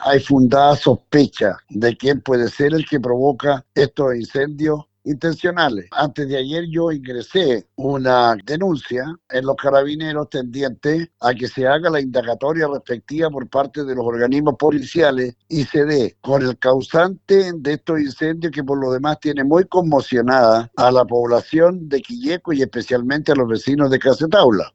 Lo señaló a La Radio el alcalde de Quilleco, Claudio Solar, quien agregó que presentaron una denuncia en Carabineros para investigar este hecho.